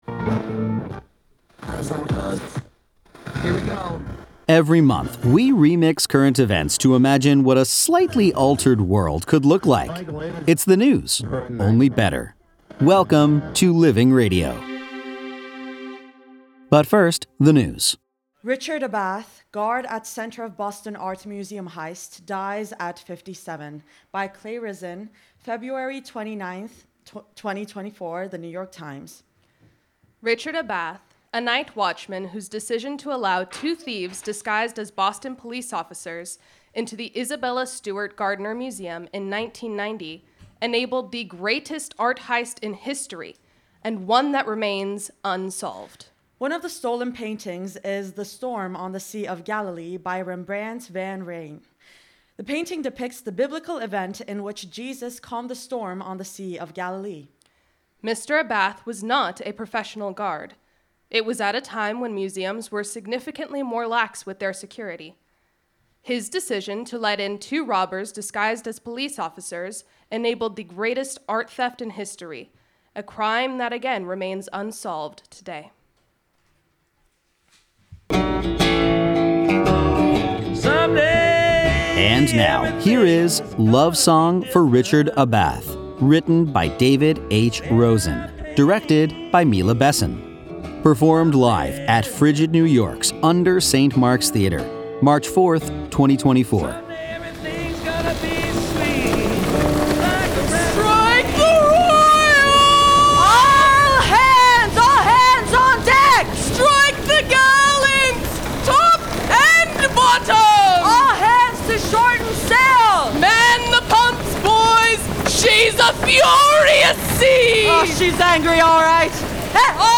performed live at UNDER St. Mark’s Theater, March 4, 2024